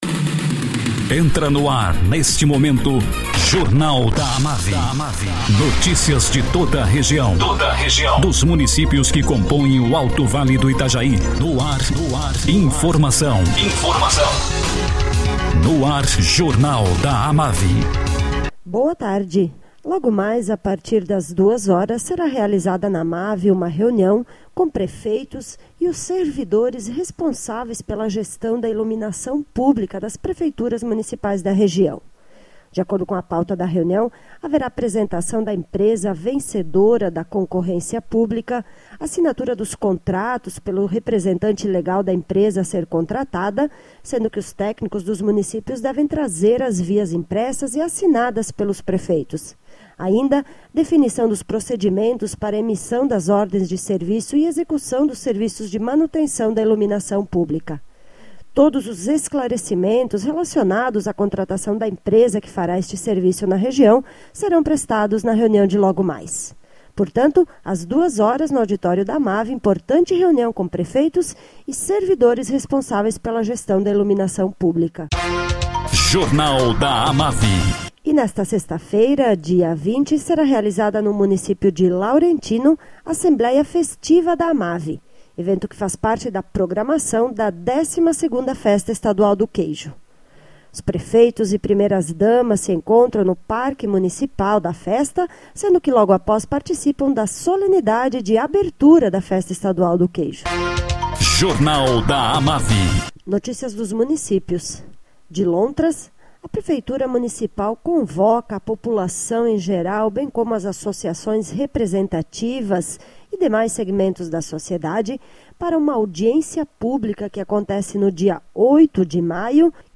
Acervo de boletins